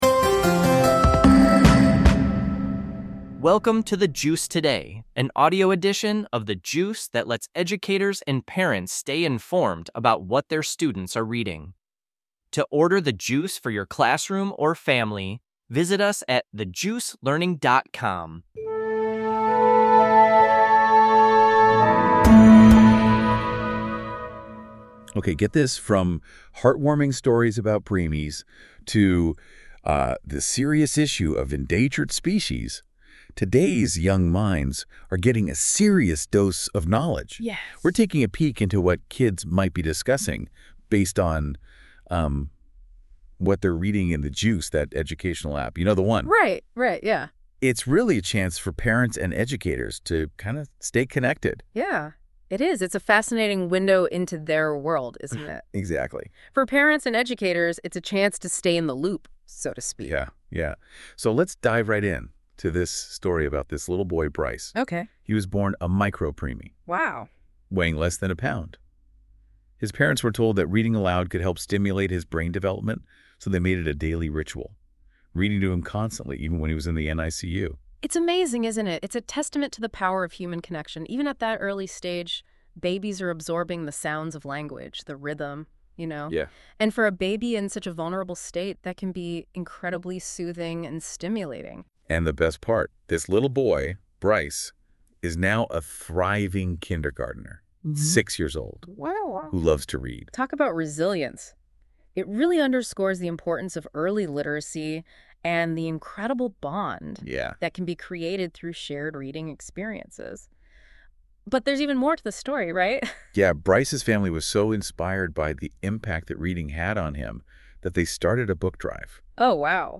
Charges Dropped after Election.Visit Us OnlineThe Juice Learning (for Educators) The Juice Today (for Parents)Production NotesThis podcast is produced by AI based on the content of a specific episode of The Juice.